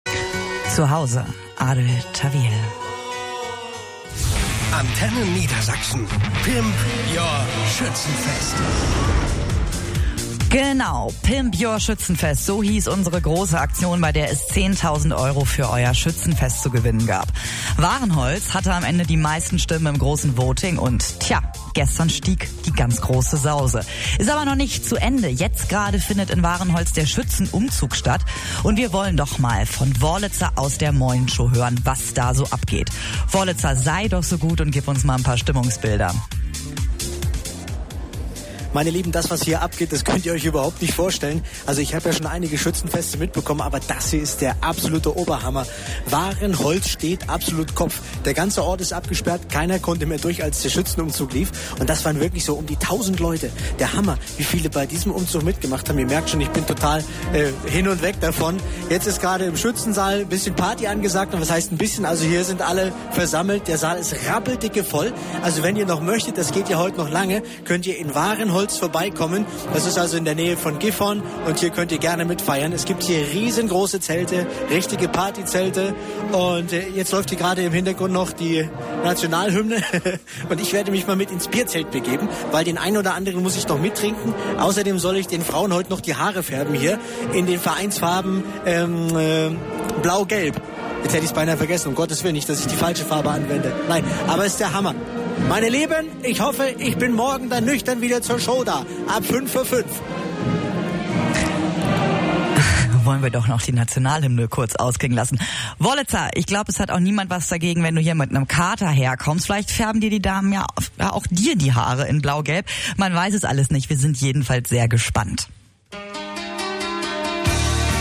Mitschnitt Antenne Niedersachsen Sonntag, 31.05. 14:46 Uhr